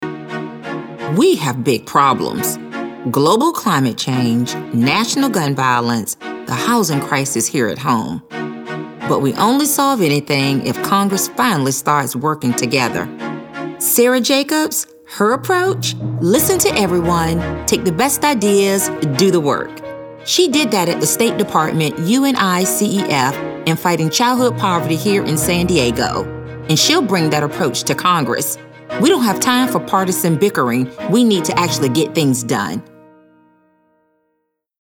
African American, caring, compelling, concerned, Gravitas, middle-age, serious, sincere, warm